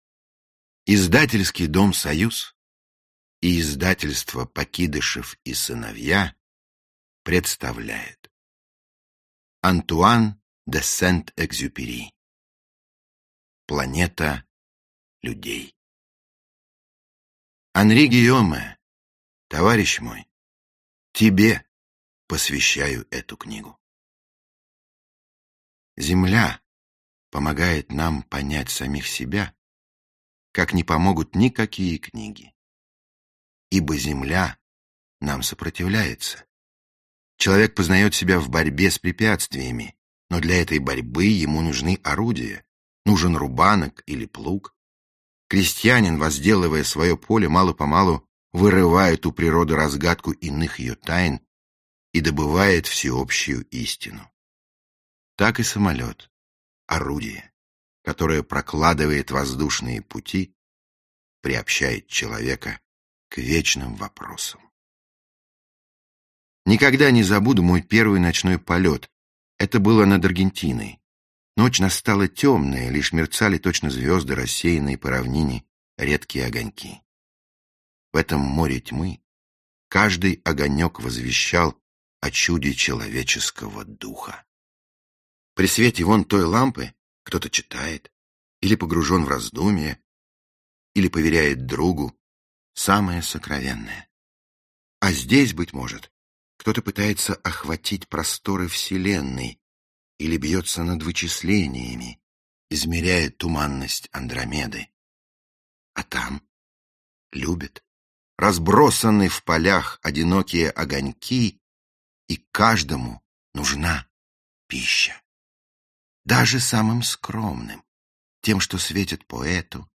Аудиокнига Планета людей | Библиотека аудиокниг
Aудиокнига Планета людей Автор Антуан де Сент-Экзюпери Читает аудиокнигу Михаил Горевой.